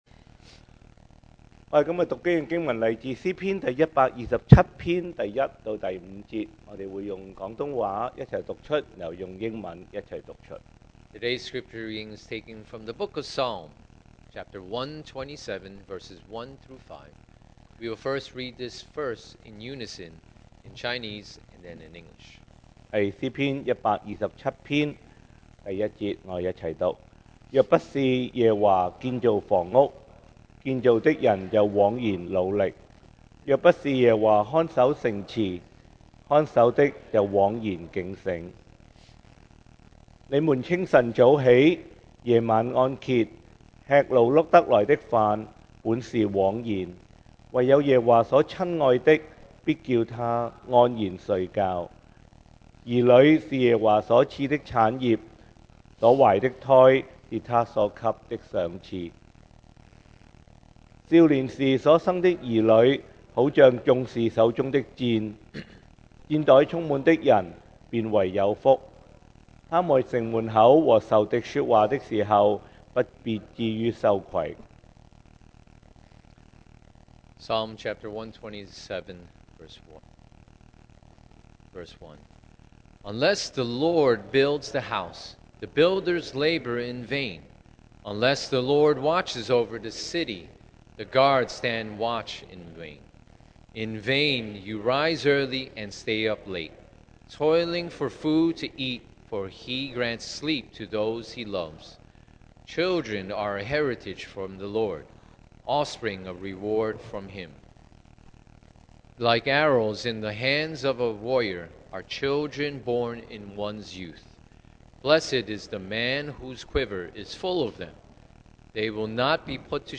2024 sermon audios
Passage: Psalm 127:1-5 Service Type: Sunday Morning